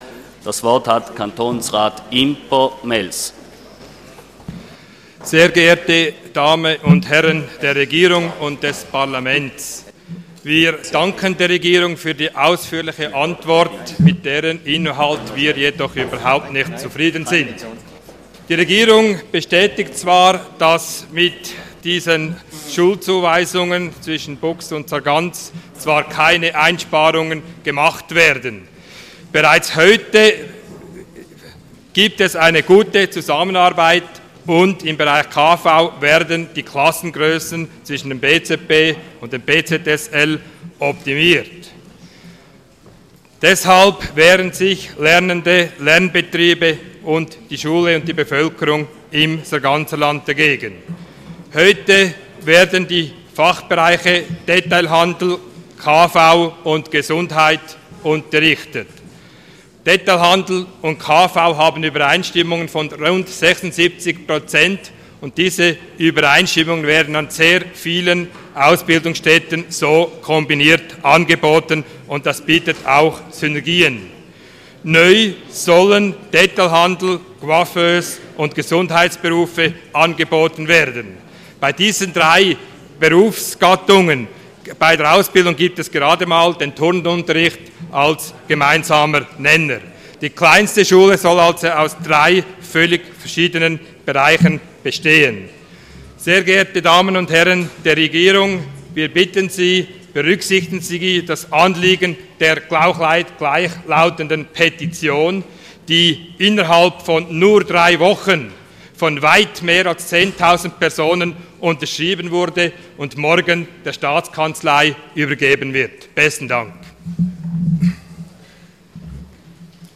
26.11.2012Wortmeldung
Session des Kantonsrates vom 26. bis 28. November 2012